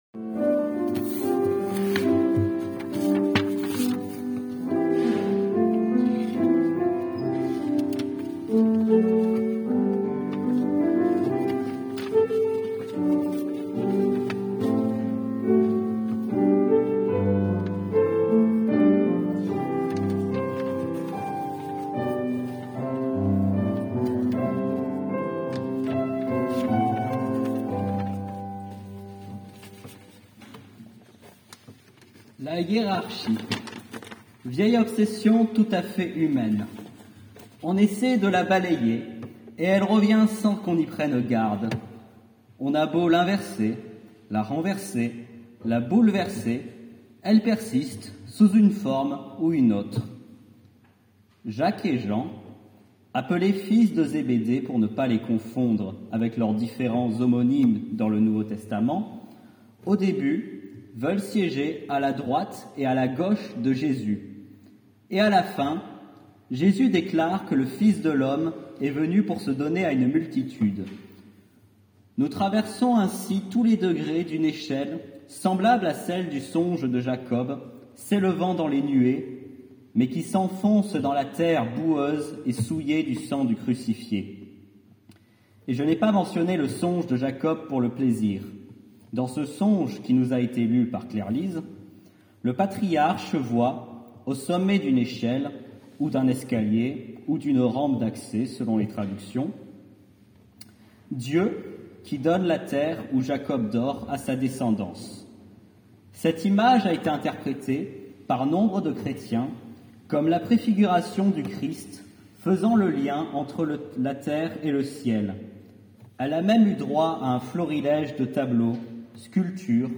Culte du 20 octobre 2024.
AUDIO DE LA PRÉDICATION